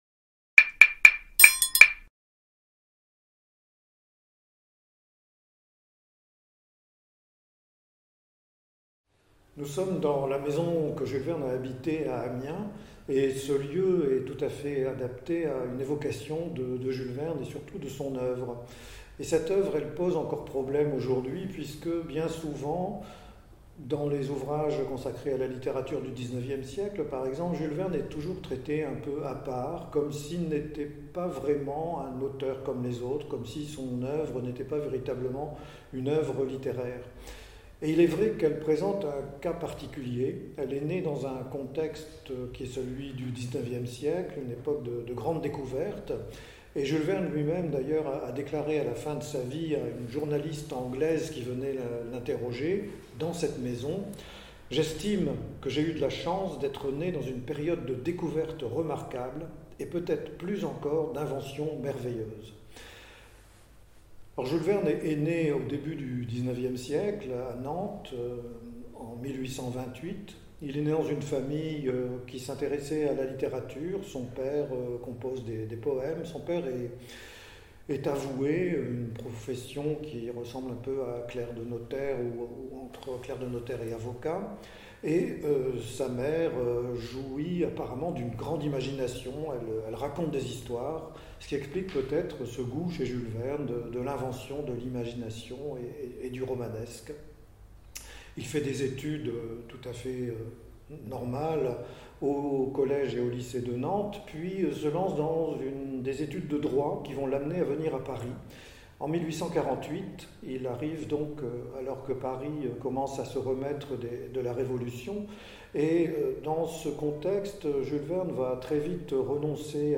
en la maison Jules Verne d'Amiens (Somme). Jules Verne (né à Nantes en 1828 et mort à Amiens en 1905, à 77 ans) vécut 34 ans (de 1871 à sa mort) dans cette maison.